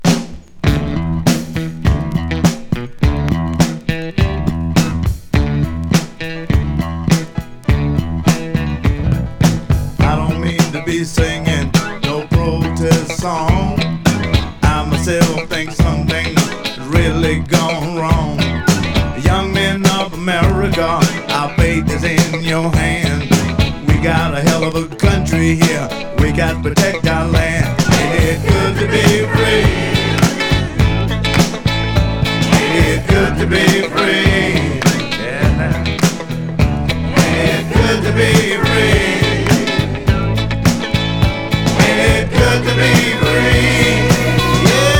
Rock, Rock'N'Roll, Funk　France　12inchレコード　33rpm　Stereo